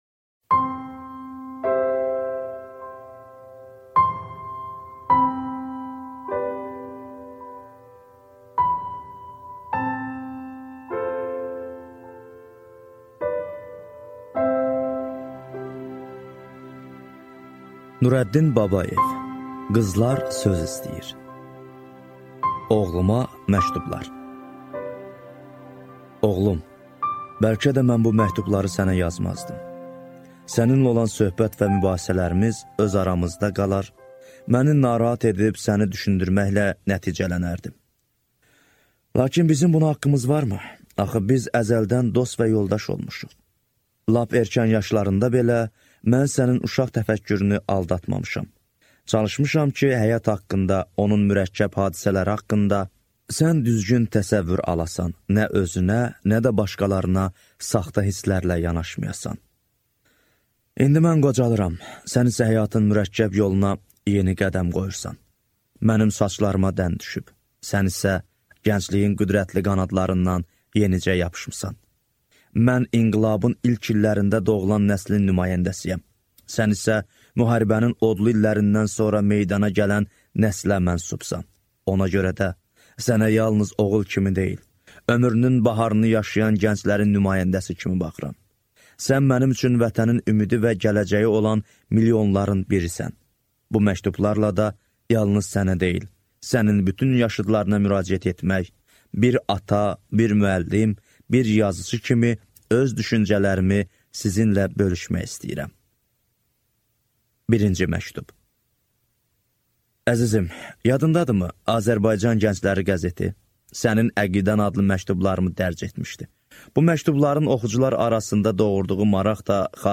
Аудиокнига Qızlar söz istəyir | Библиотека аудиокниг